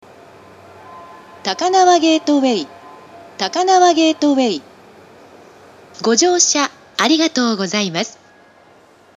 １番線到着放送